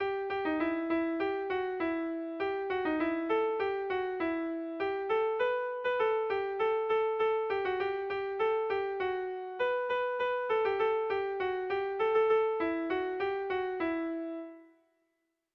Air de bertsos - Voir fiche   Pour savoir plus sur cette section
Irrizkoa
Elduain < Tolosaldea < Gipuzkoa < Euskal Herria
ABDEFG